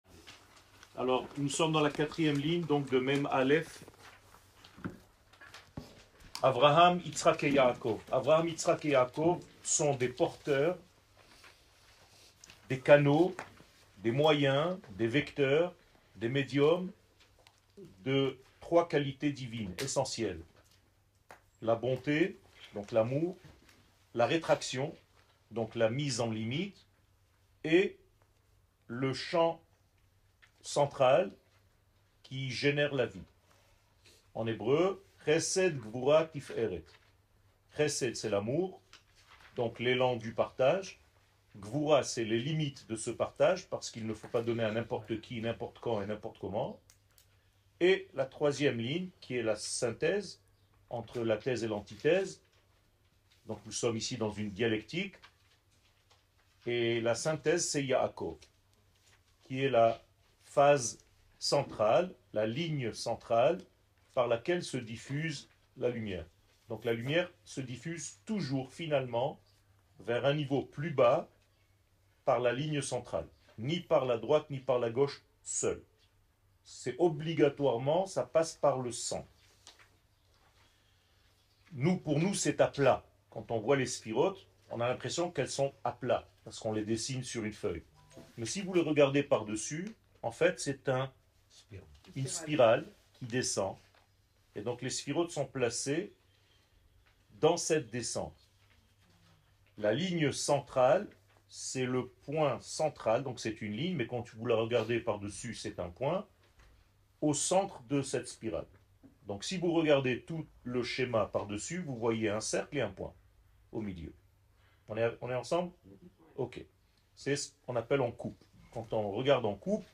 La droite et le cercle Eretz Israel 01:00:36 La droite et le cercle Eretz Israel שיעור מ 14 אפריל 2024 01H 00MIN הורדה בקובץ אודיו MP3 (55.47 Mo) הורדה בקובץ וידאו MP4 (212.3 Mo) TAGS : שיעורים קצרים